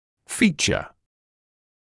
[‘fiːʧə][‘фиːчэ]черта, признак, свойствоfacial features черты лица